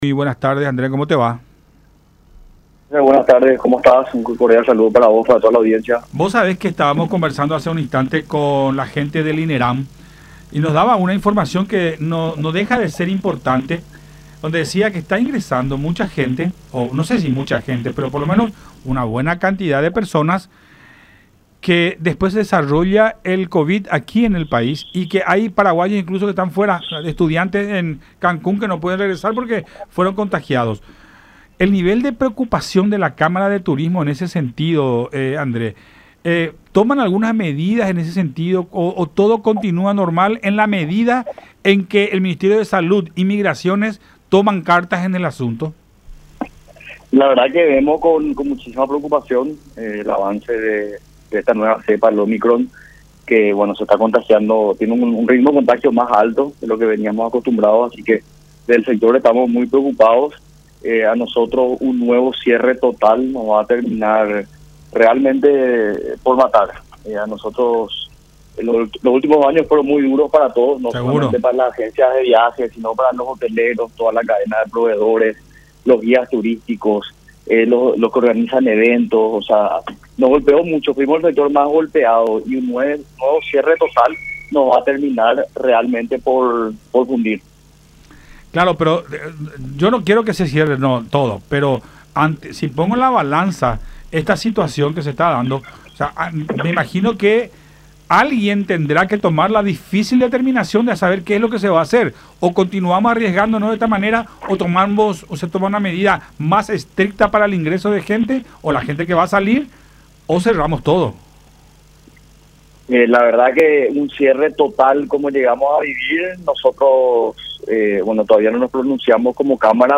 en diálogo con Buenas Tardes La Unión.